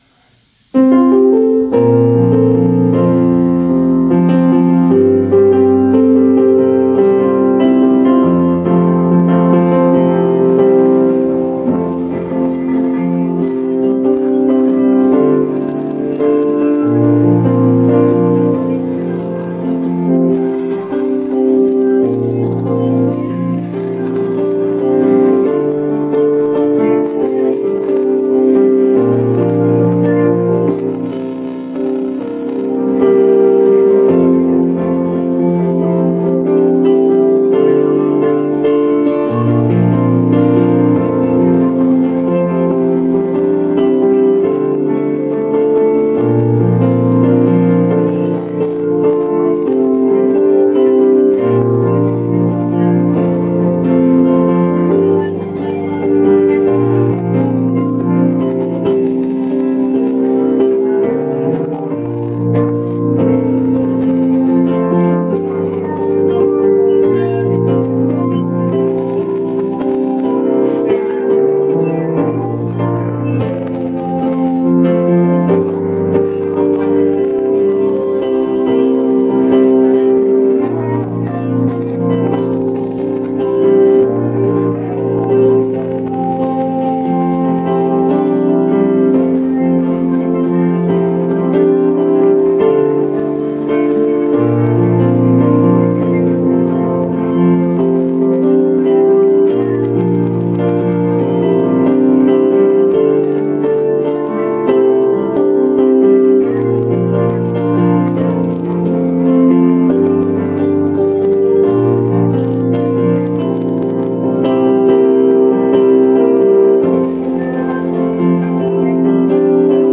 Awit-ng-Pag-ibig-Dakilang-Pag-ibig-minus-one.mp3